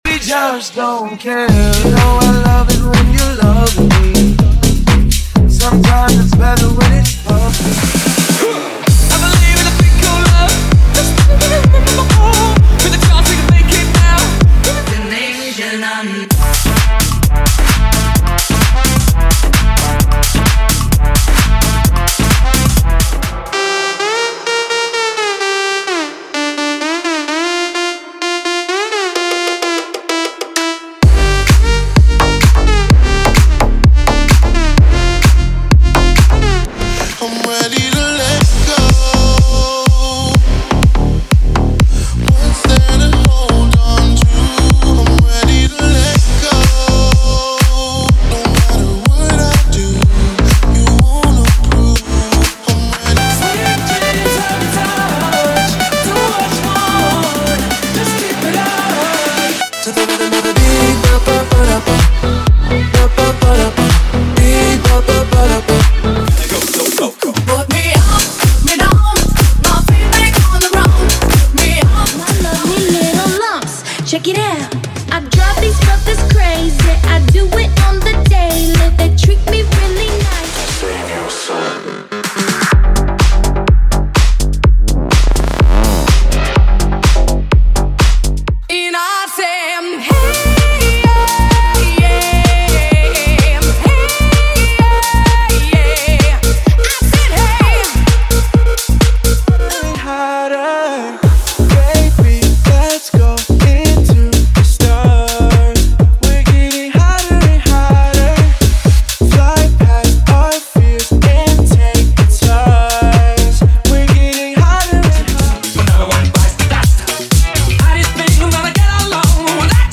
DEEP HOUSE = 100 Músicas
Sem Vinhetas
Em Alta Qualidade